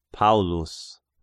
For the curious, these are the correct pronunciations: Polykarpos(Polycarp) , Paulus(Paul).